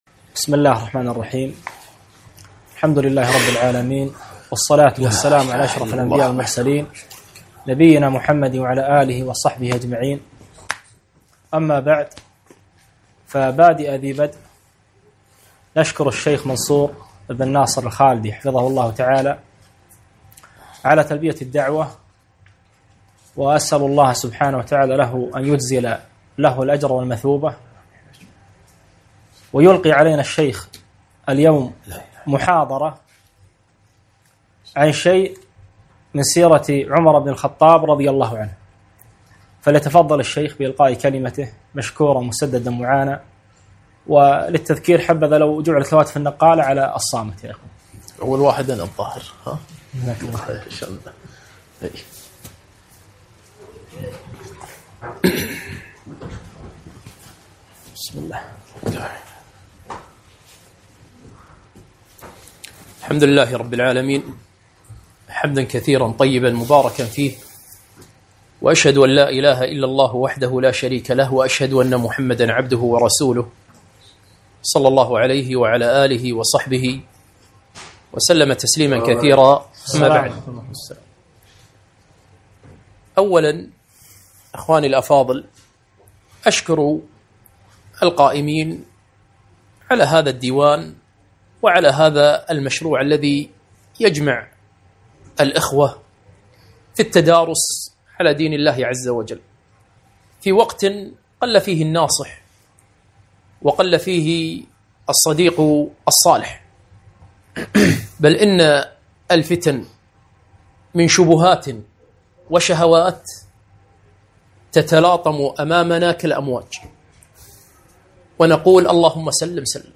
محاضرة - سيرة الفاروق رضي الله عنه